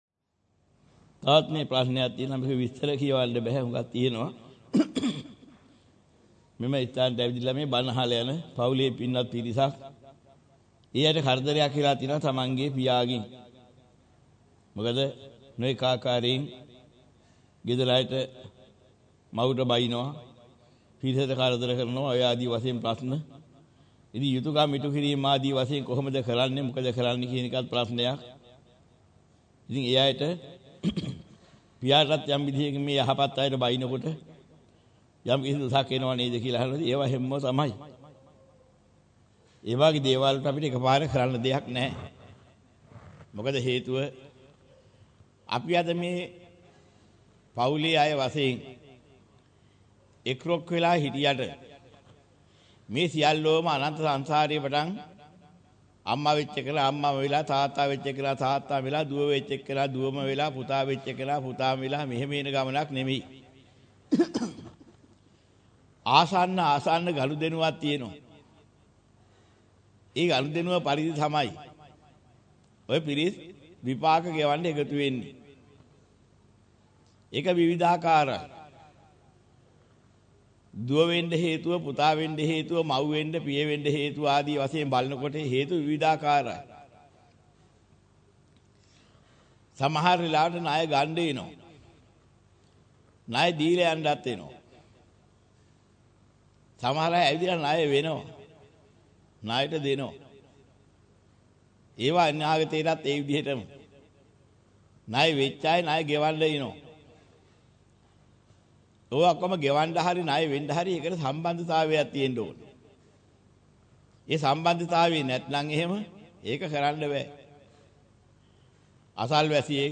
වෙනත් බ්‍රව්සරයක් භාවිතා කරන්නැයි යෝජනා කර සිටිමු 26:18 10 fast_rewind 10 fast_forward share බෙදාගන්න මෙම දේශනය පසුව සවන් දීමට අවැසි නම් මෙතැනින් බාගත කරන්න  (11 MB)